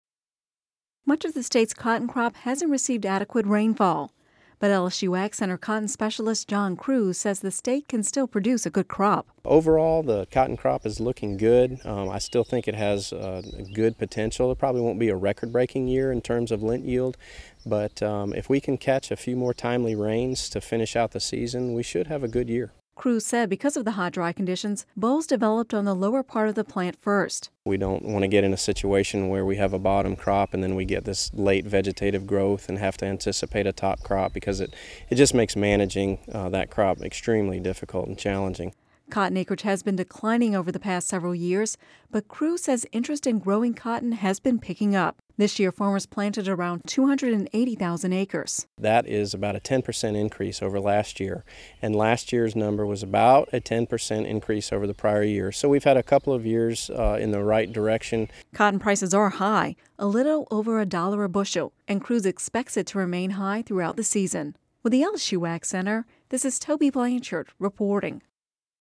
(Radio News 07/07/11) Much of Louisiana’s cotton crop hasn’t received adequate rainfall